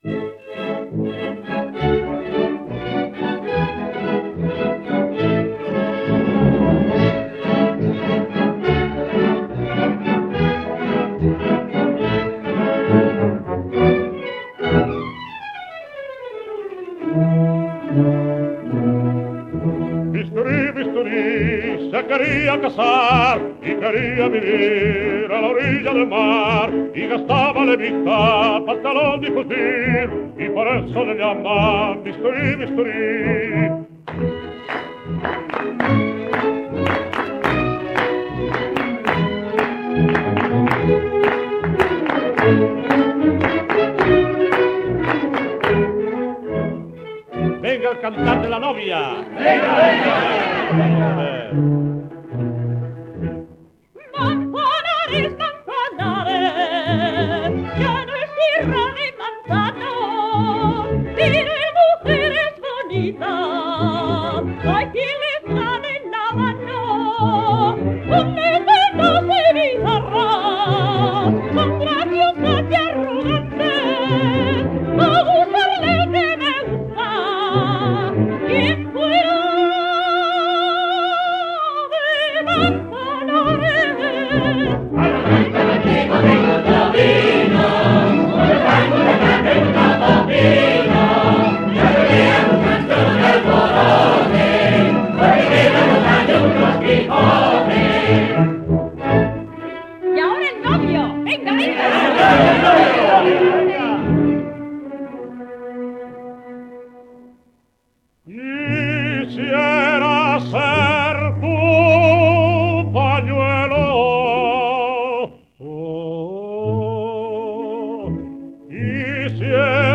coro, orquesta [78 rpm